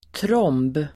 Uttal: [tråm:b]